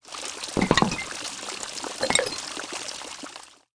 sfx_tavern_trouble_beer_fountain.mp3